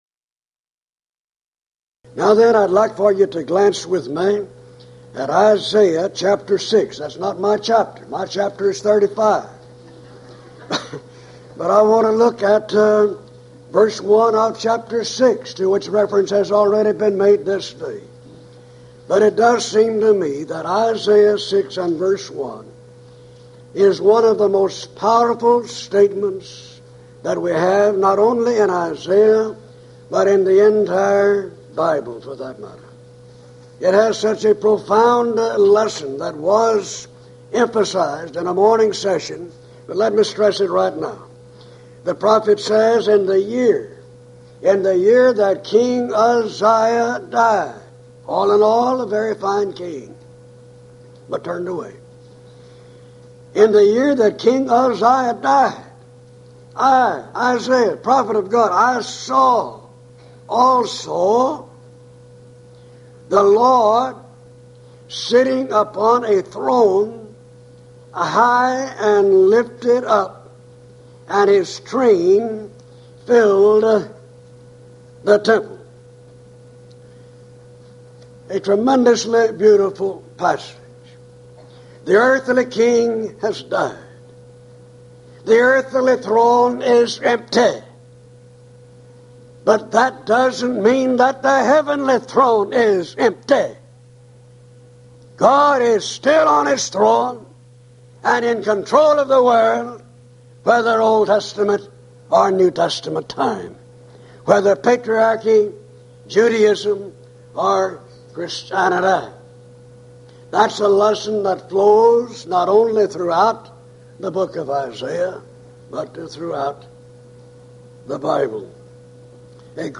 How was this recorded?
Event: 1995 HCB Lectures